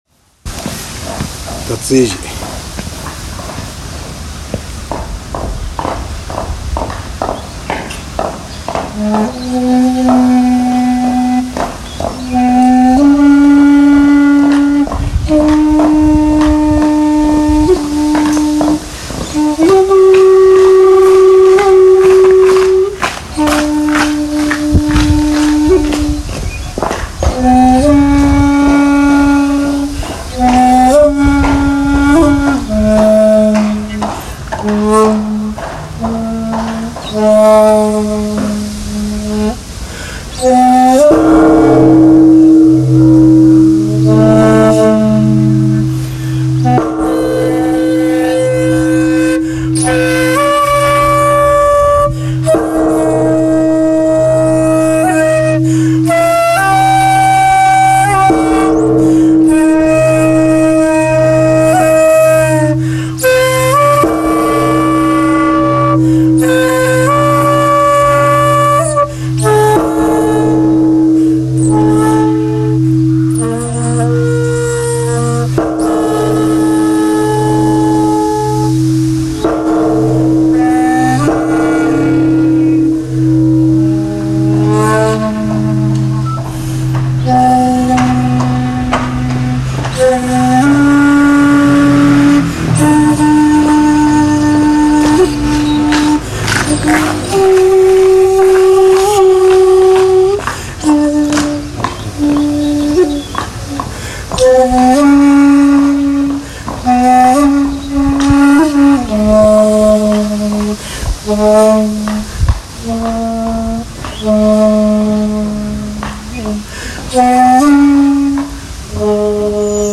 まだ時間があるのでまずはいつものようにお参りをし、尺八を吹奏することにしました。
朝の梵鐘の響く中での尺八となりました。
◆◆　（尺八音源：立江寺梵鐘と「水鏡」）